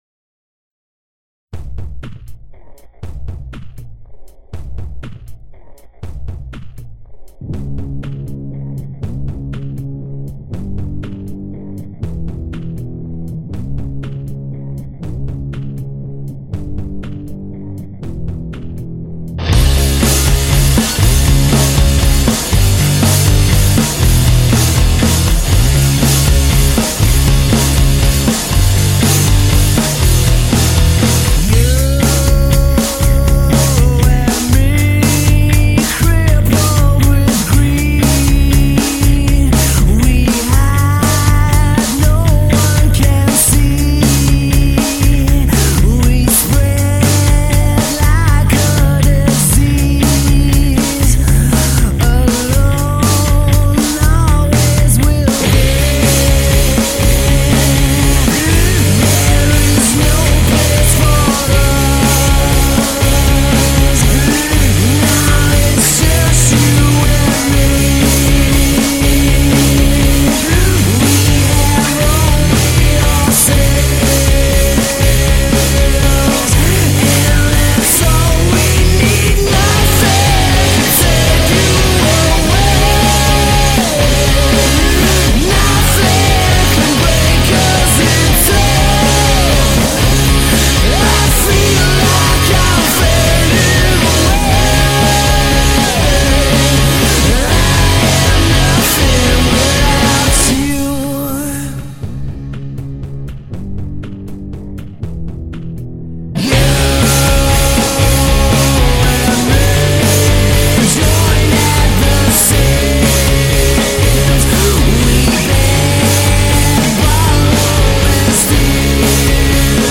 4 piece alternative rock band
dark, brooding, yet seamless sound containing real energy